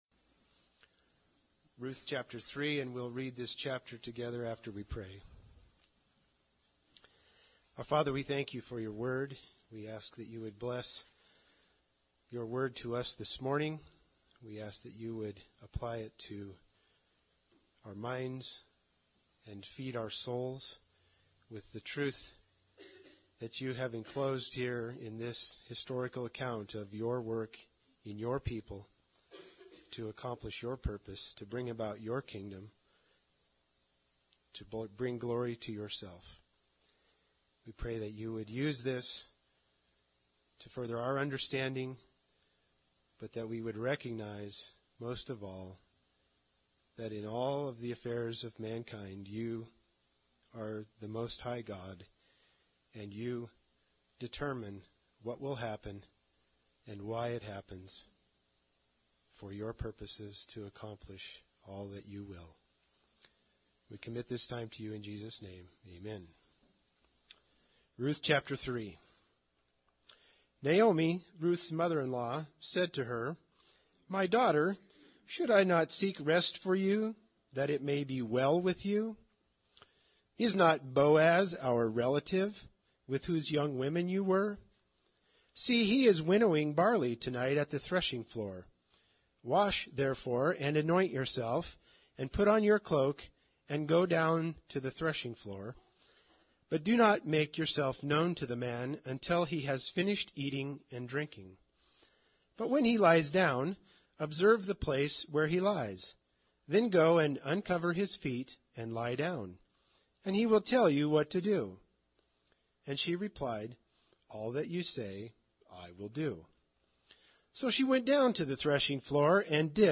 Ruth 3 Service Type: Sunday Service Bible Text